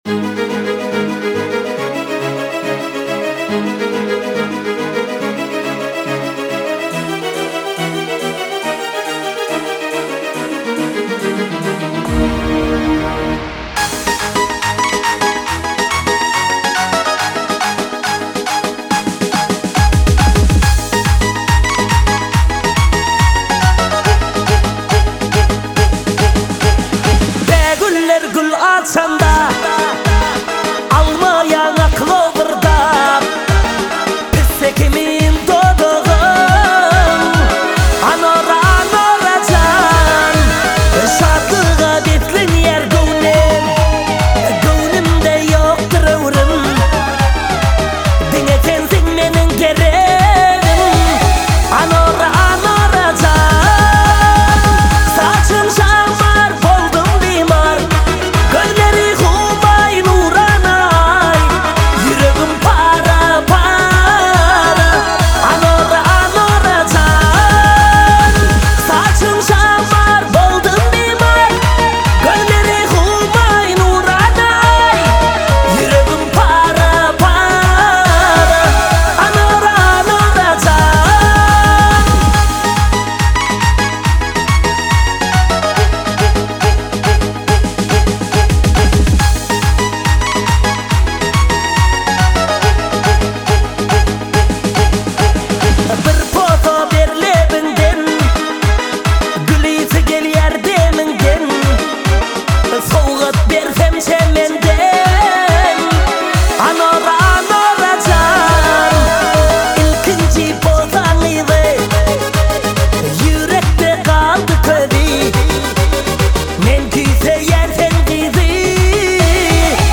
Трек размещён в разделе Узбекская музыка / Альтернатива.